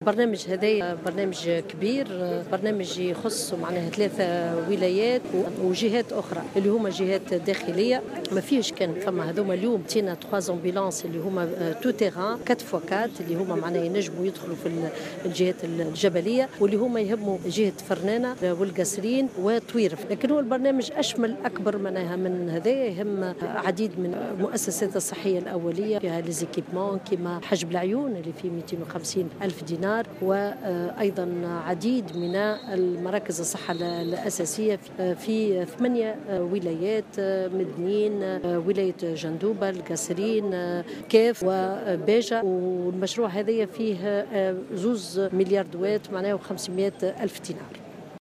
وقالت وزيرة الصحة سميرة مرعي في تصريح لمراسل "الجوهرة أف أم" أن البرنامج سيشمل مؤسسات صحية في العديد من الولايات على غرار مدنين و جندوبة و القصرين و الكاف وباجة وذلك بكلفة جملية ناهزت 2.5 مليون دينار.